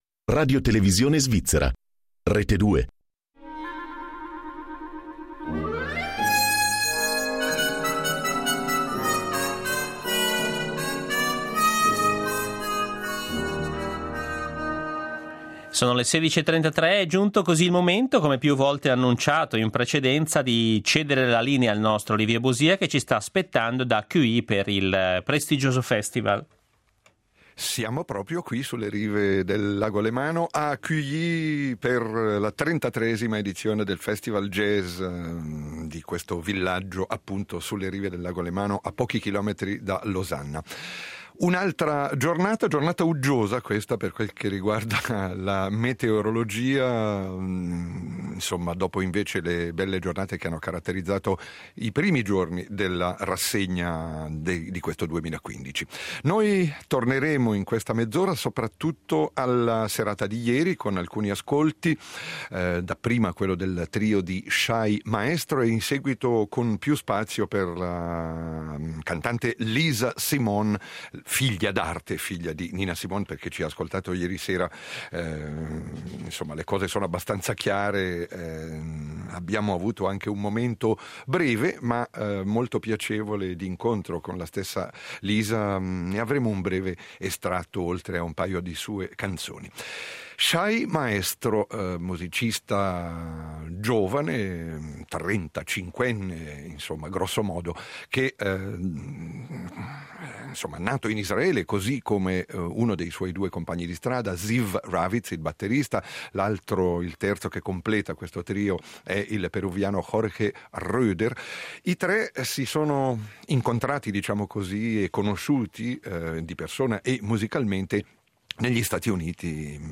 jazz contemporaneo